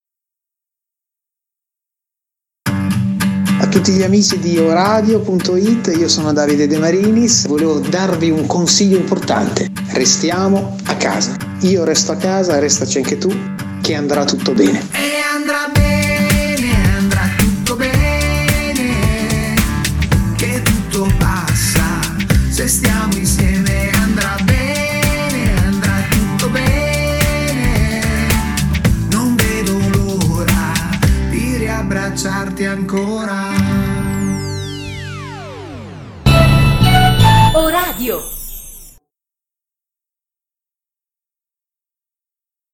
jingles musicali